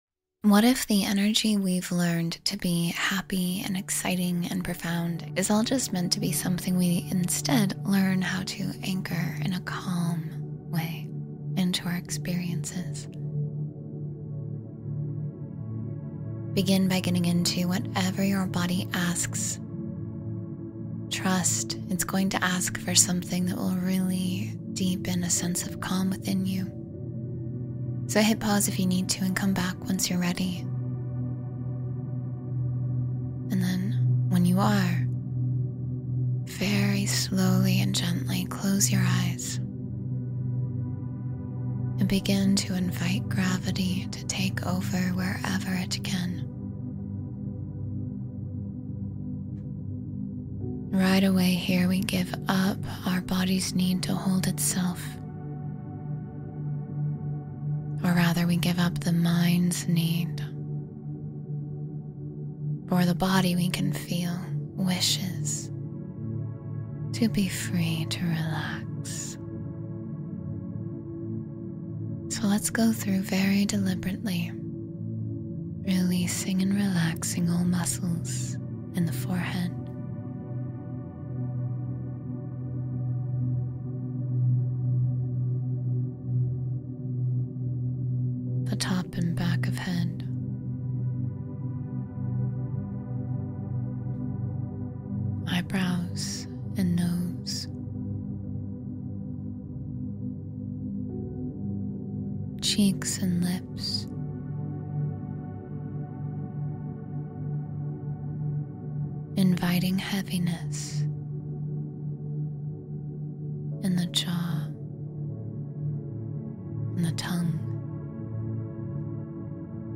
Drift into Deep Restful Sleep — Guided Meditation for Peaceful Slumber